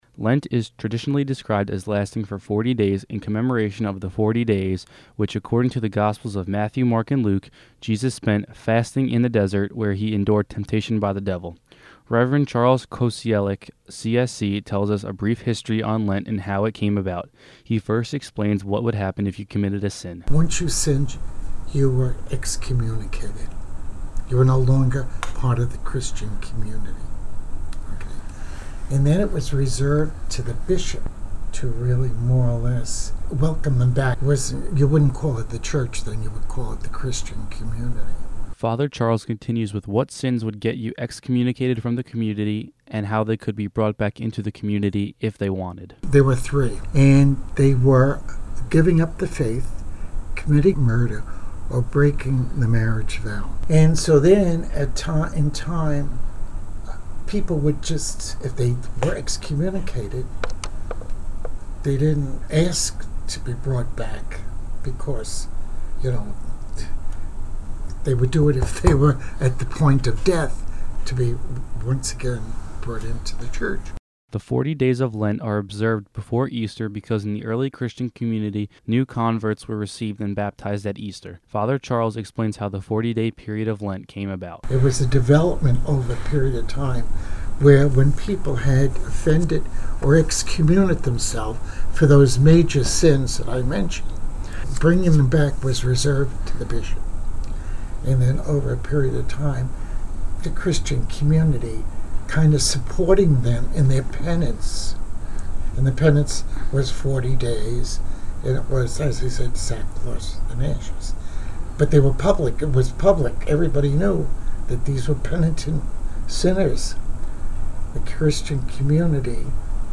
NEWS AT 5: Lenten Season Begins